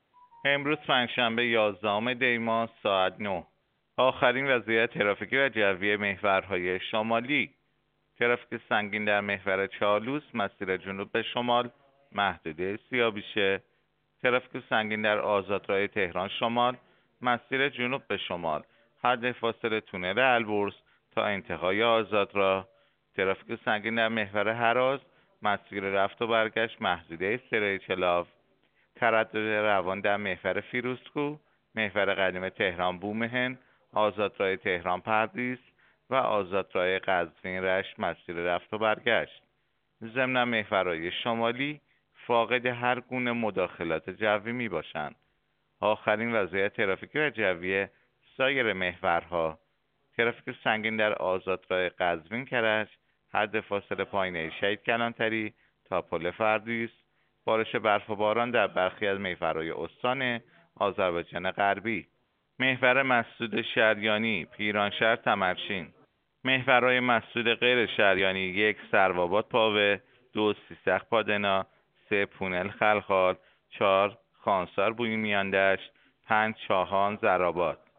گزارش رادیو اینترنتی از آخرین وضعیت ترافیکی جاده‌ها ساعت ۹ یازدهم دی؛